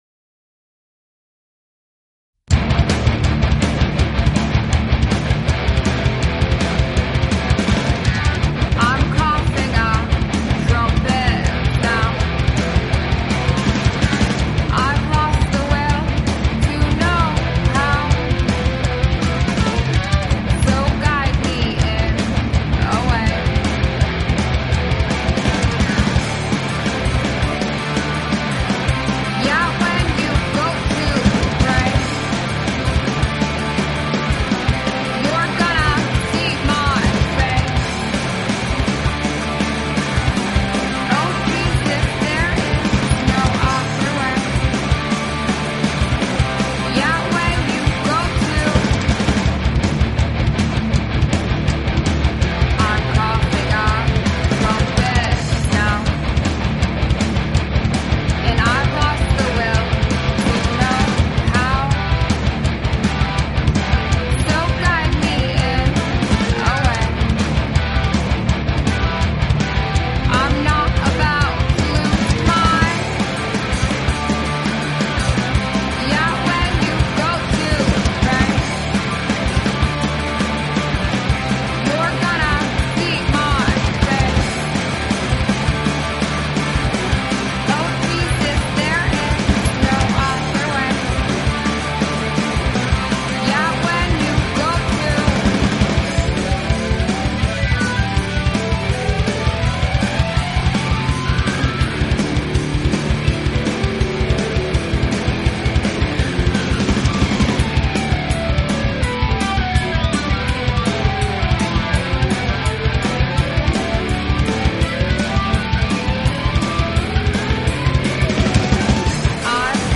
Talk Show Episode, Audio Podcast, Modified_Perceptions and Courtesy of BBS Radio on , show guests , about , categorized as
Modified Perceptions is the internet radio talk show for MODWorld.